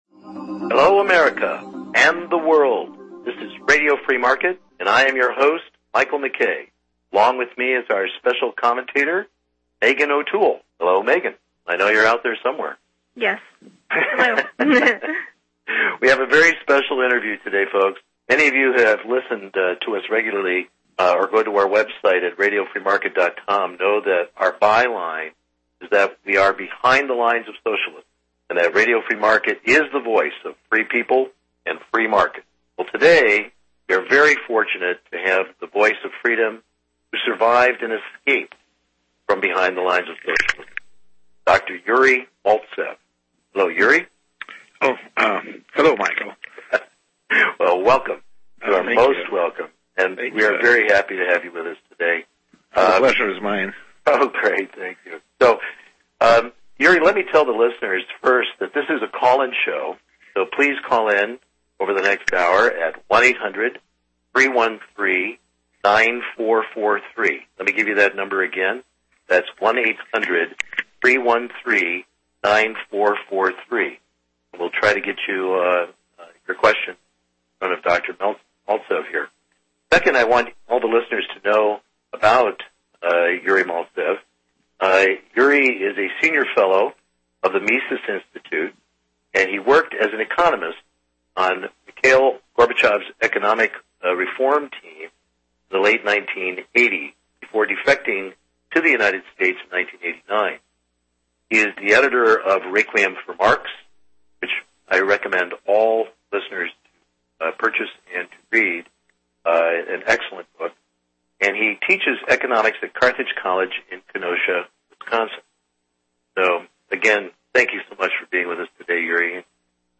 We discussed what really happened in Russia under Gorbachev and what history tells us we can expect from Socialistic Medicine in the USA. This is an excellent and timely interview you will want to download and share with your friends and family.